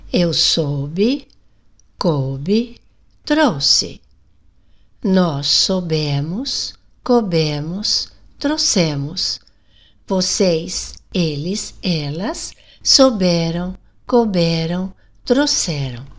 No português do Brasil, é comum “comermos” algumas letras na linguagem oral.